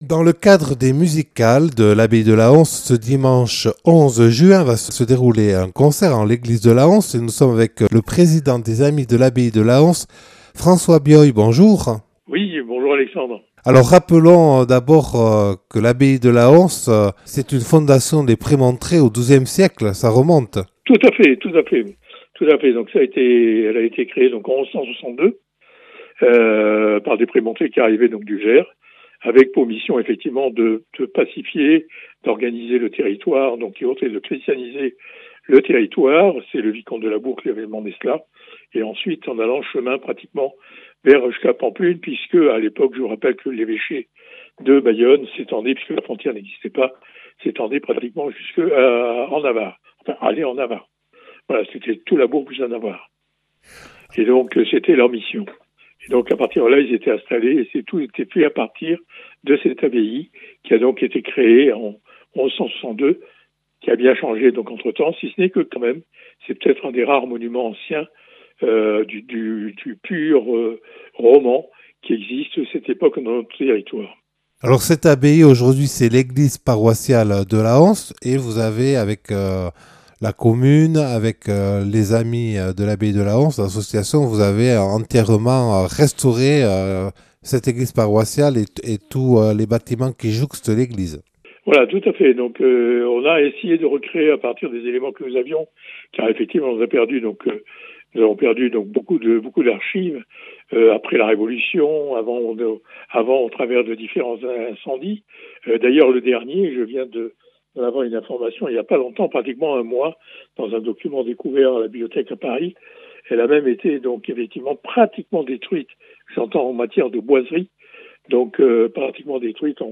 Accueil \ Emissions \ Infos \ Interviews et reportages \ L’ensemble vocal Octavus en concert à l’abbaye de Lahonce le dimanche 11 (...)